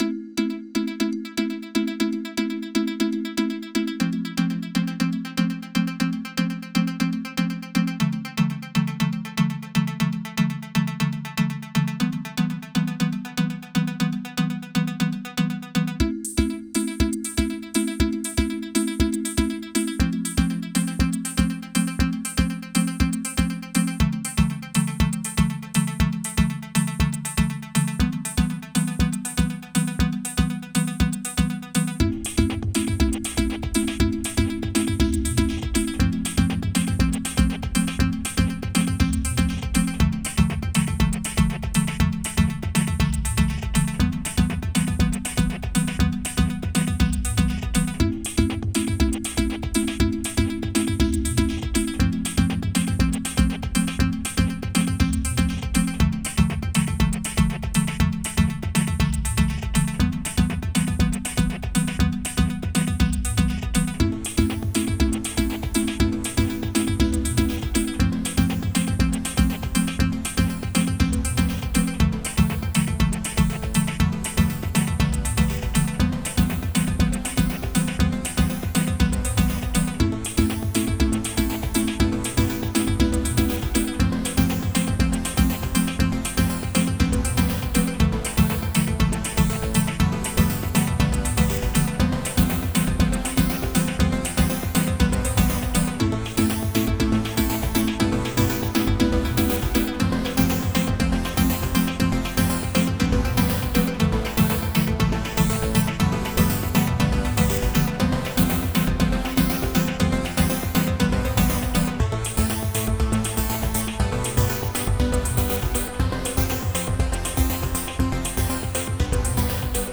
Beeps and snakes in honor of a loved one who inspires me so.